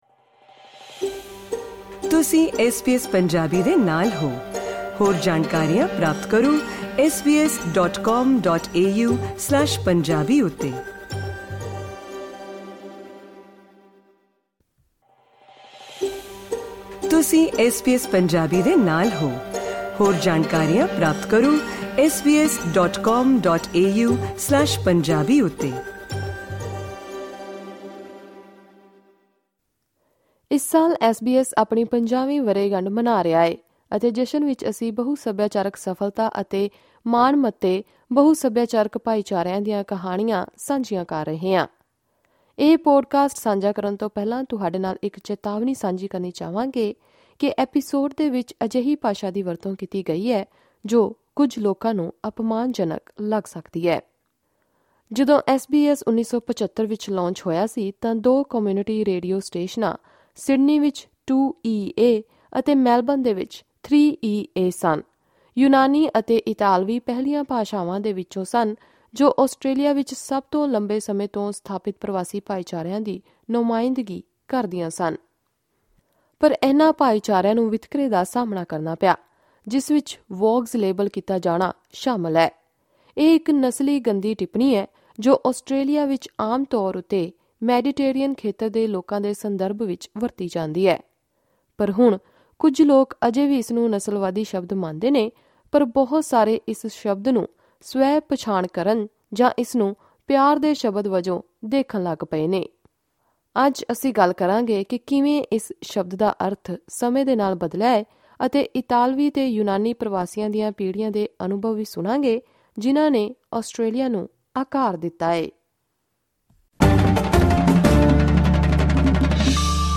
These Greek and Italian migrants share their experiences of Australia and their place within it.